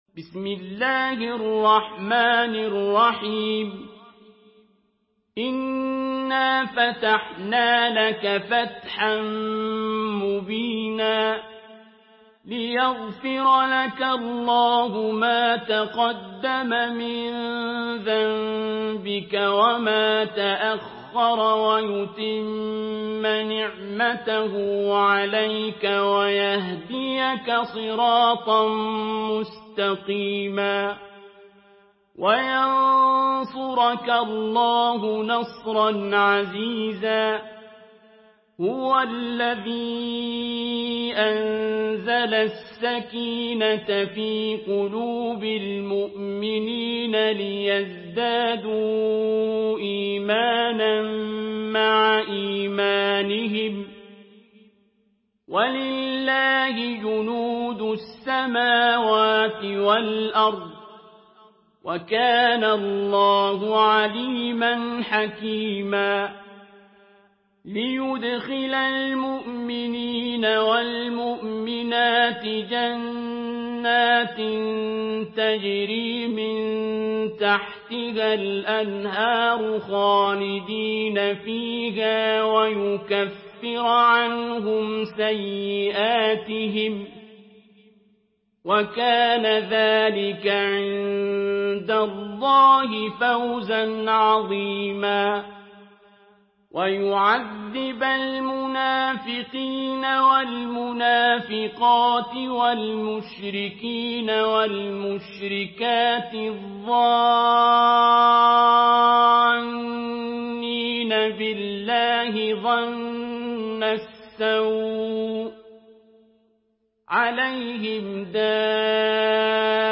Surah আল-ফাতহ MP3 by Abdul Basit Abd Alsamad in Hafs An Asim narration.
Murattal